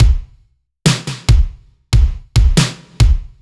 Index of /musicradar/french-house-chillout-samples/140bpm/Beats
FHC_BeatC_140-02_KickSnare.wav